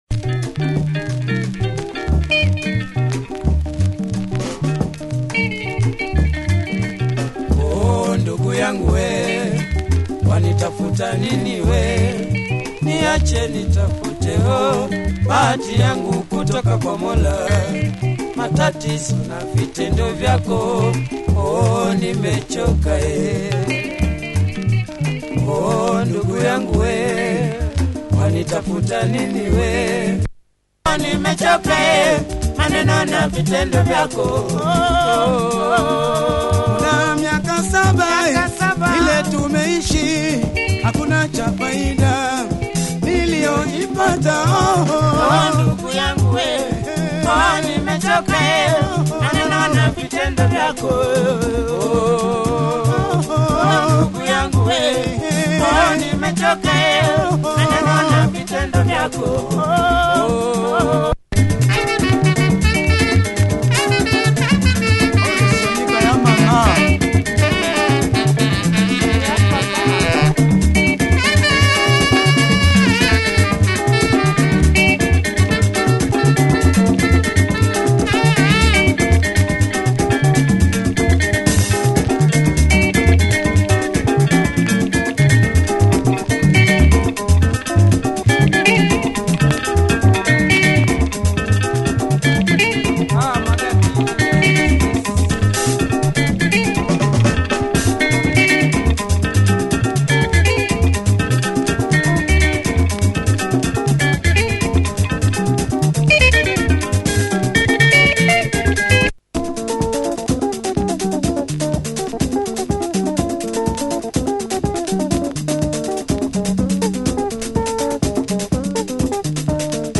Great breakdown!